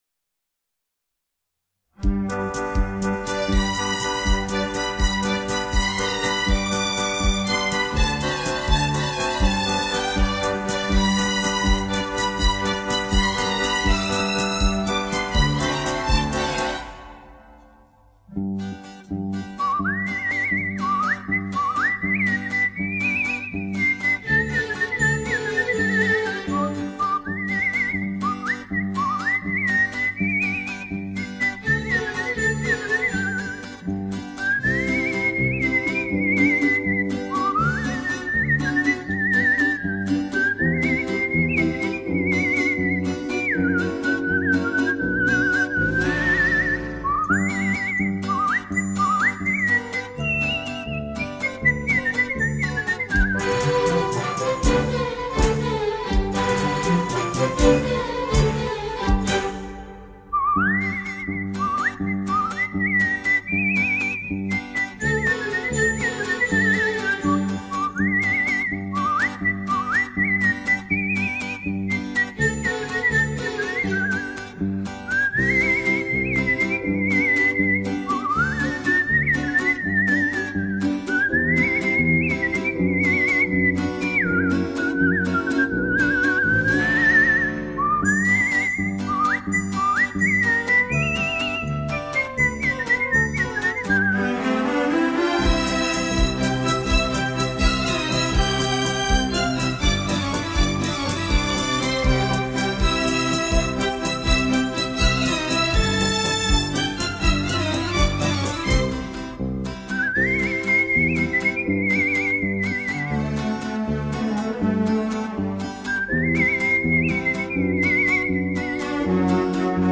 节奏明快 旋律优美
轻音乐一般以小型乐队加以演奏，结构简单、节奏明快、旋律优美。